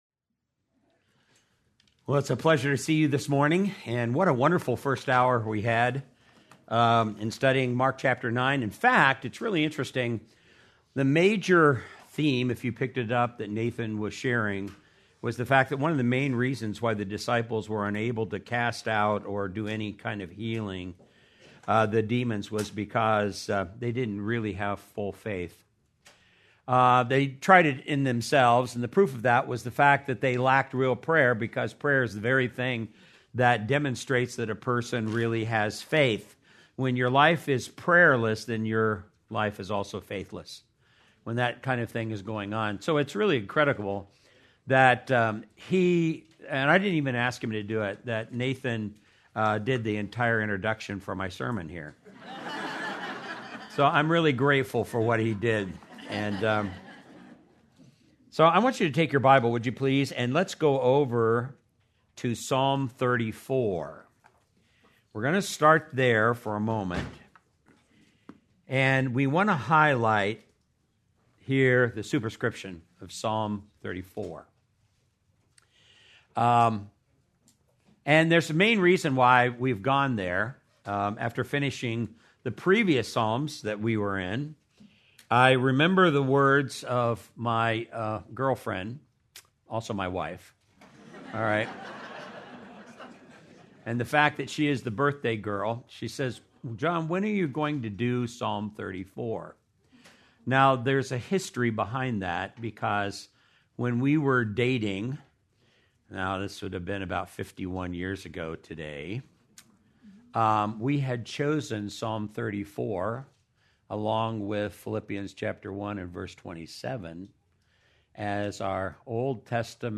April 19, 2026 - Sermon